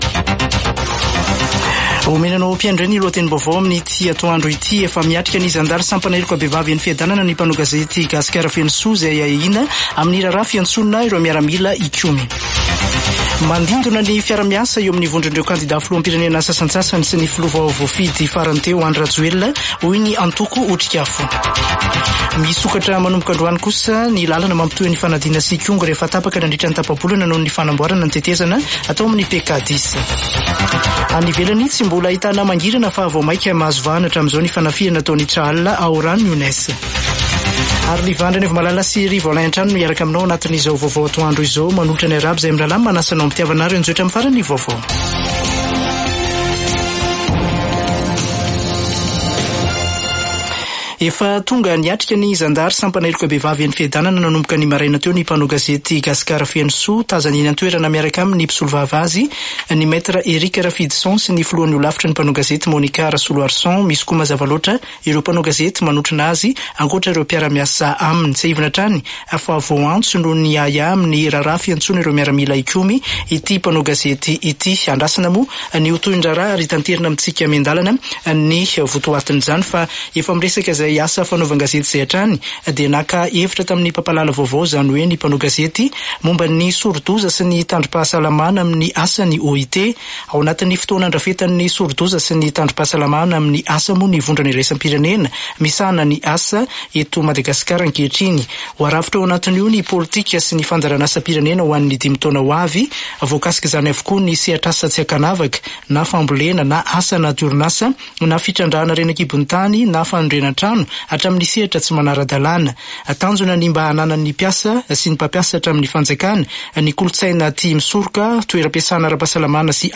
[Vaovao antoandro] Zoma 8 desambra 2023